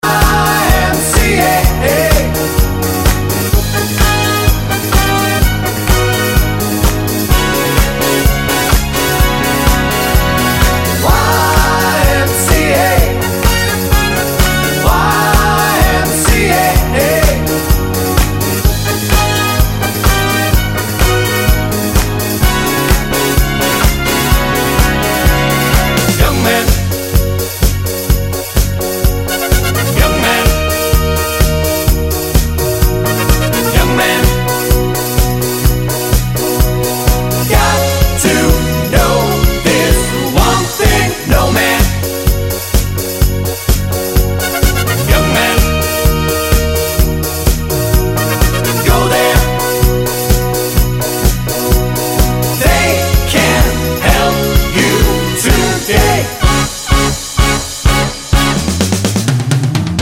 Twofers Medley Disco 4:54 Buy £1.50